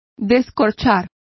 Complete with pronunciation of the translation of uncork.